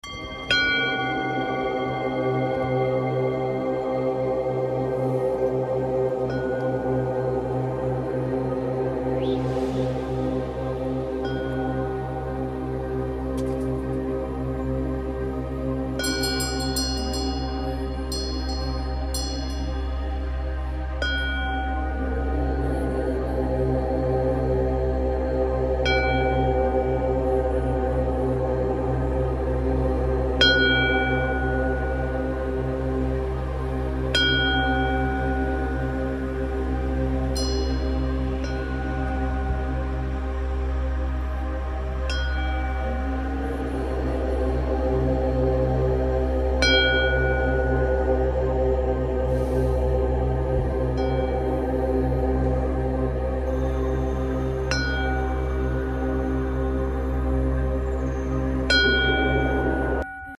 💸 Money Block Remover ASMR sound effects free download